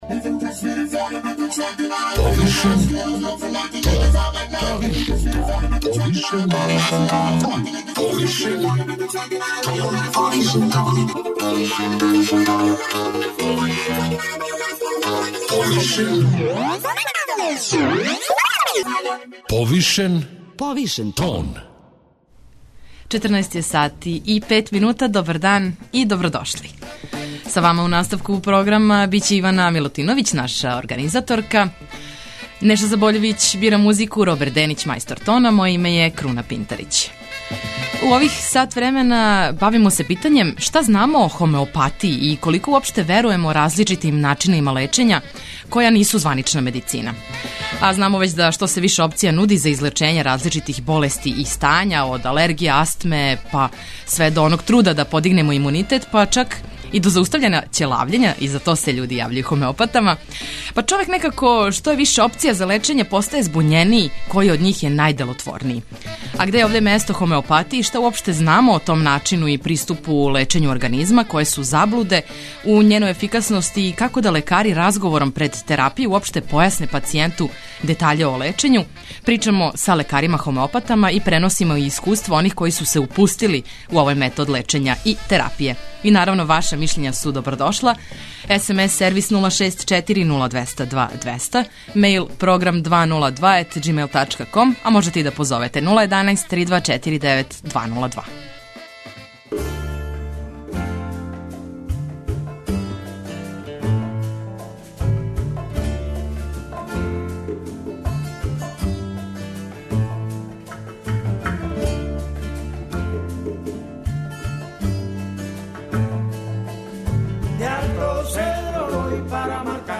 Где је овде место хомеопатији, шта уопште знамо о том начину и приступу лечењу организма, које су заблуде у њеној ефикасности и како да лекари разговором пред терапију уопште појасне пацијенту детаље о лечењу, причамо са хомеопатама и преносимо искуства оних који су се упустили у овај метод лечења и терапије.